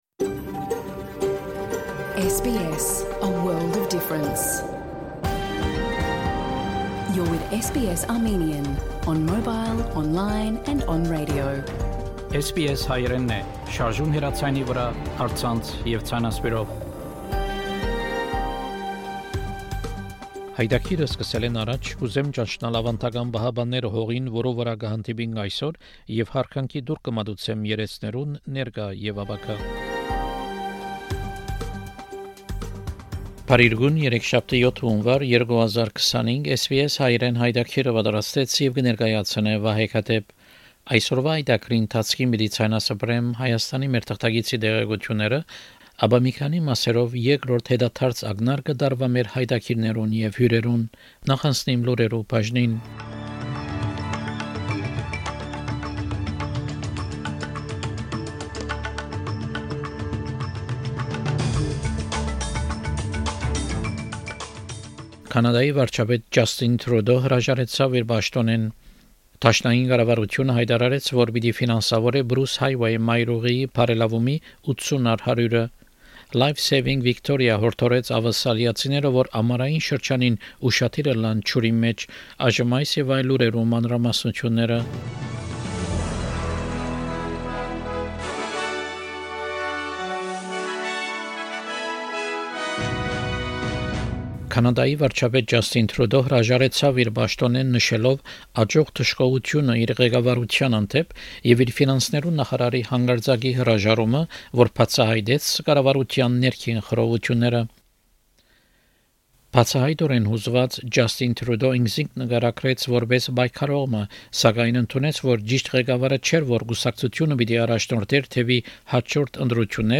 SBS Հայերէնի աւստրալիական և միջազգային լուրերը քաղուած 7 Յունուար 2025 յայտագրէն: SBS Armenian news bulletin from 7 January 2025 program.